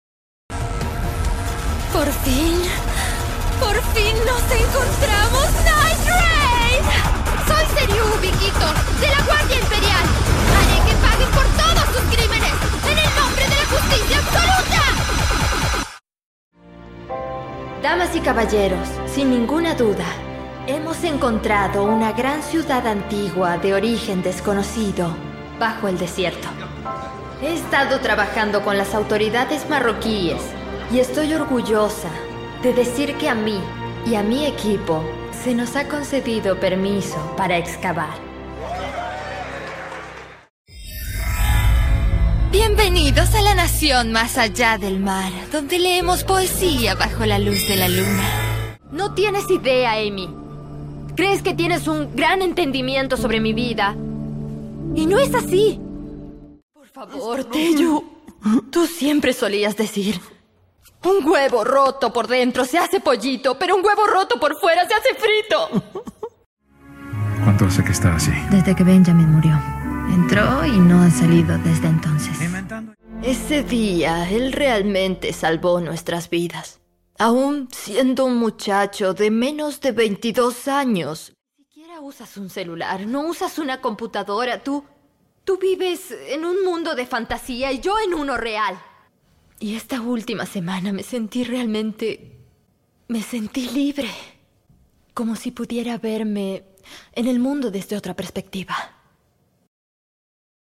Demo doblaje
Demo-corto-neutro-mp3.mp3